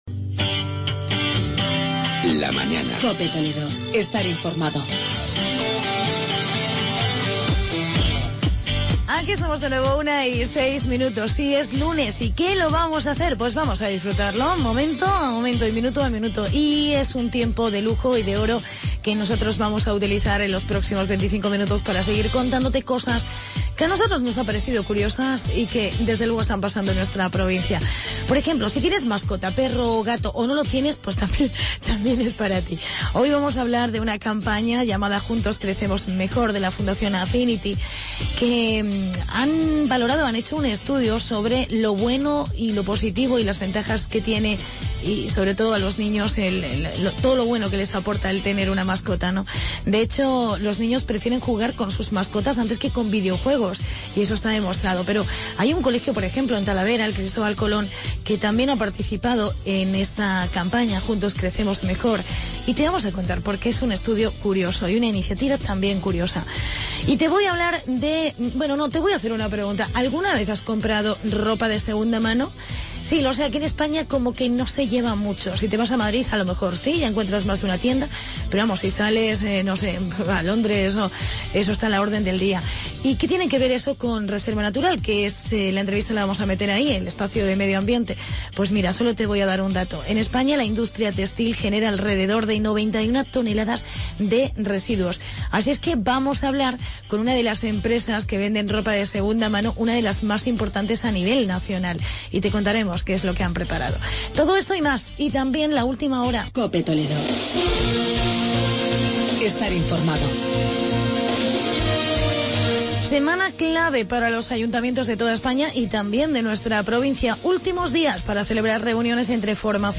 COPE TALAVERA